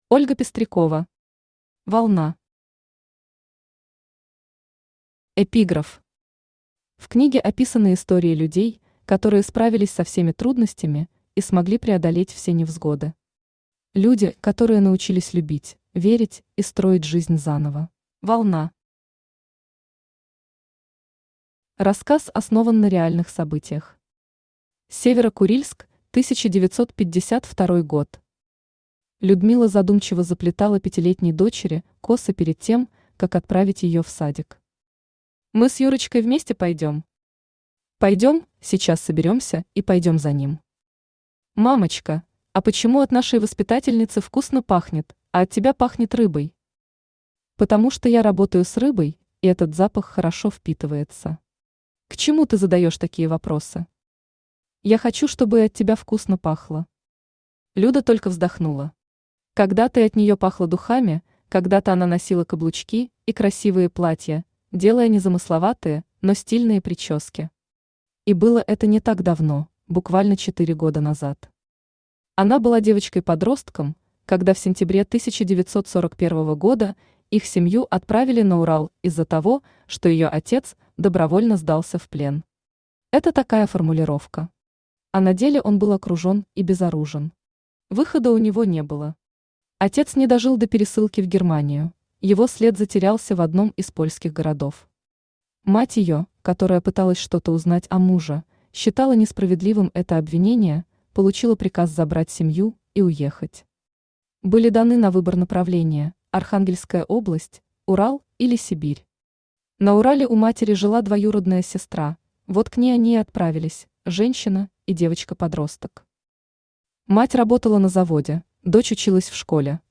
Аудиокнига Волна | Библиотека аудиокниг
Aудиокнига Волна Автор Ольга Владимировна Пестрякова Читает аудиокнигу Авточтец ЛитРес.